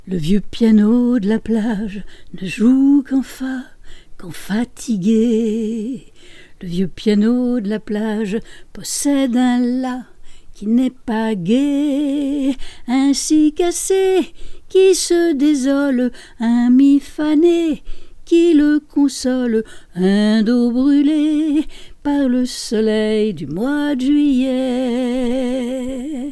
vOIX CHANTÉ POUR CASTING ASSURANCE